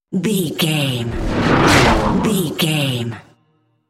Whoosh fast airy cinematic
Sound Effects
Atonal
Fast
futuristic
intense